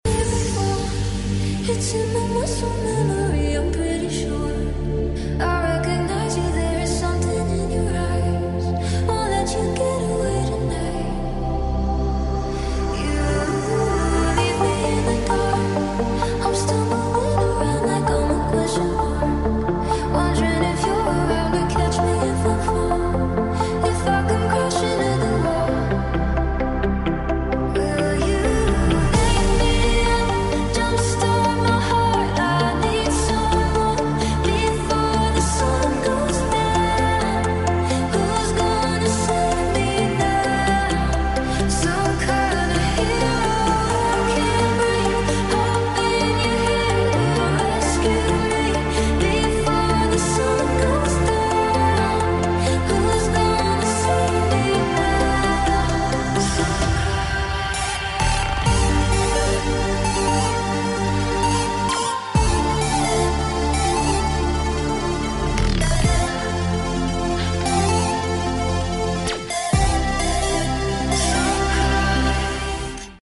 Jungle Dutch